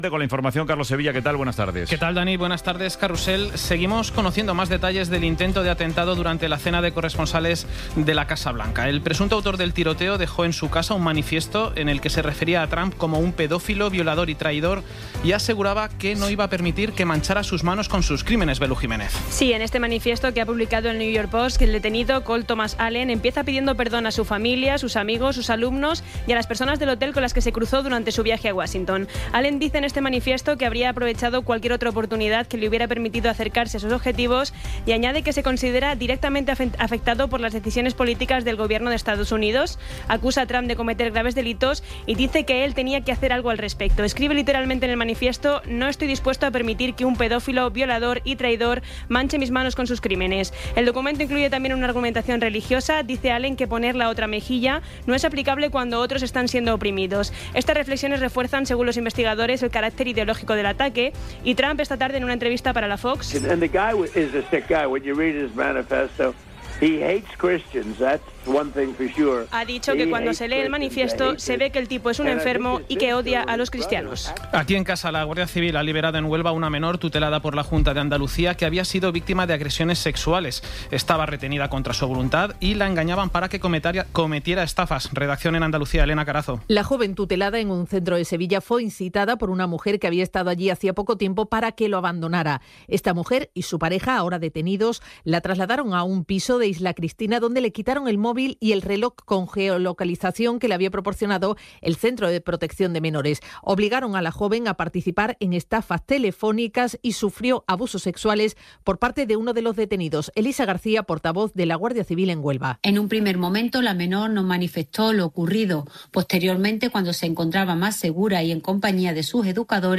Resumen informativo con las noticias más destacadas del 26 de abril de 2026 a las ocho de la tarde.